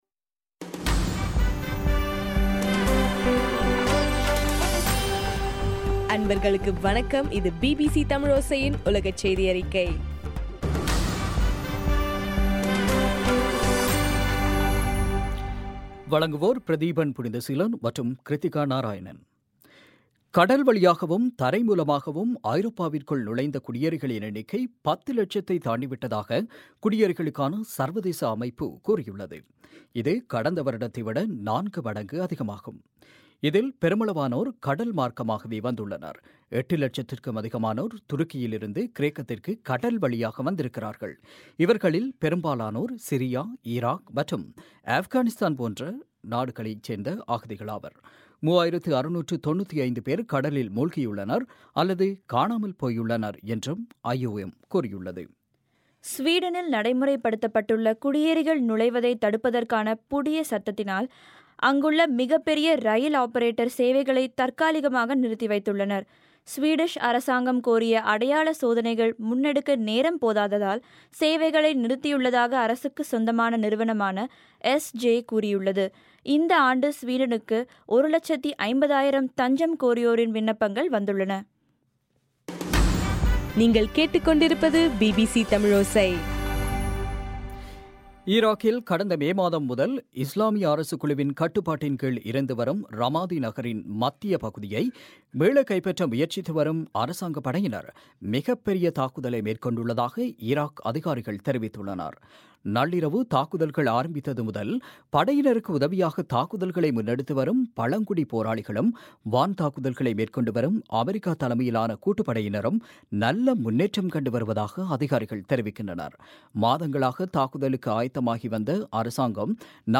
டிசம்பர் 22, 2015 பிபிசி தமிழோசையின் உலகச் செய்திகள்